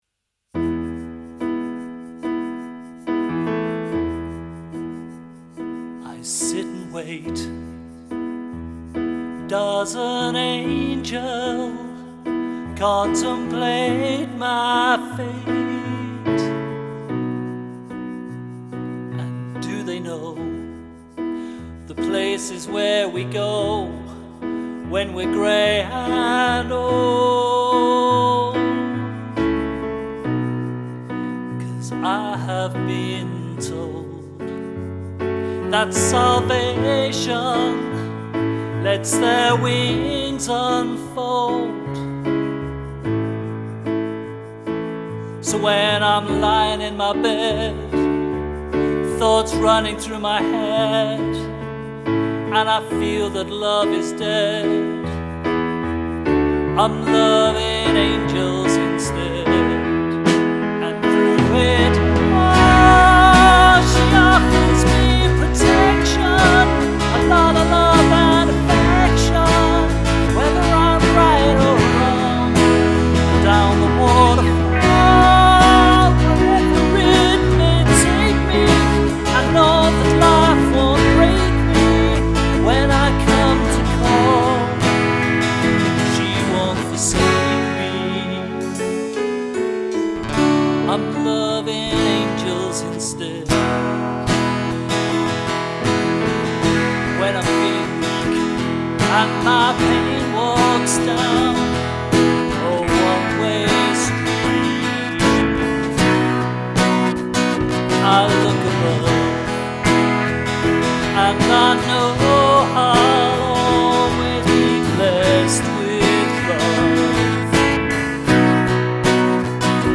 shaker makes an appearance ...